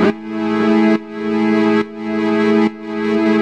Index of /musicradar/sidechained-samples/140bpm
GnS_Pad-MiscB1:2_140-A.wav